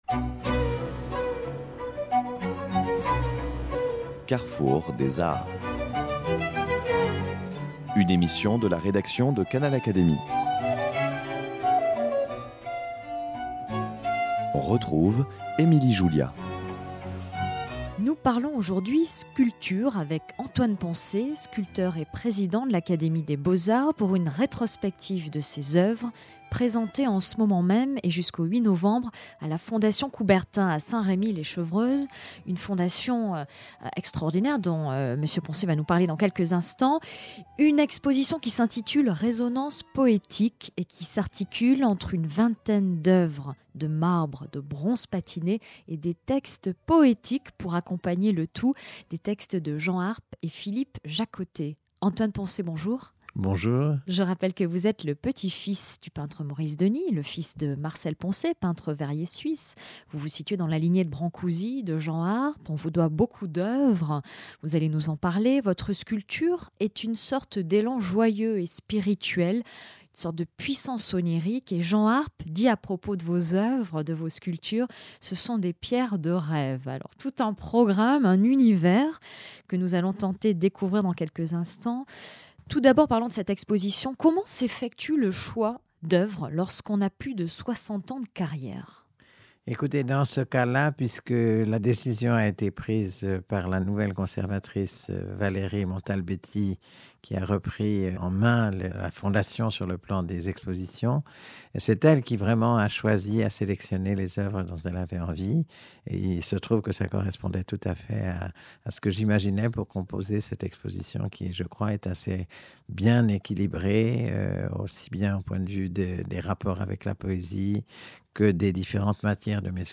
Le sculpteur Antoine Poncet nous parle en studio de Résonances poétiques, une exposition qui rend hommage à son travail et à son oeuvre.
L'actuel président de l'Académie des beaux-arts, Antoine Poncet, parle d'un ton tranquille et assuré des oeuvres qu'il présente jusqu'au 8 novembre à Saint-Rémy les Chevreuse.
Nous l'écoutons dans le studio de Canal Académie.